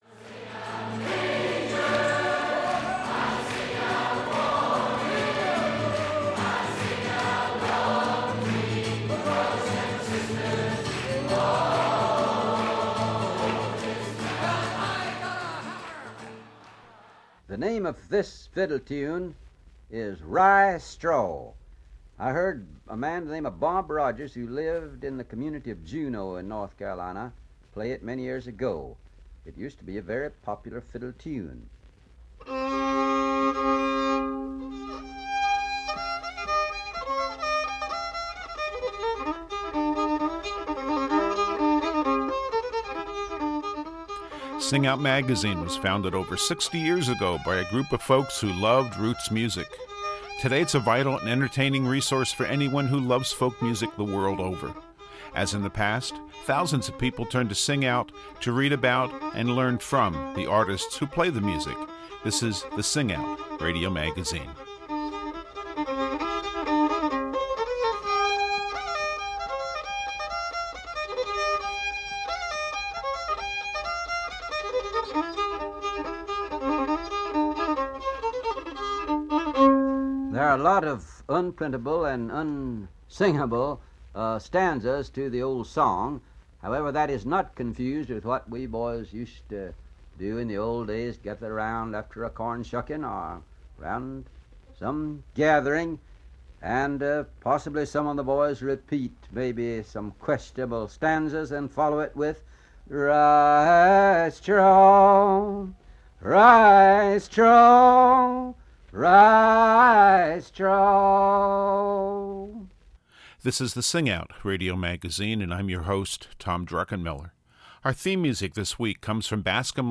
In celebration of these great events, we'll hear classic music from the first Newport Folk Festival in 1959, travel to Galax Virginia for the Fiddler's Convention, and visit Colorado for two of the Centennial State’s finest music events.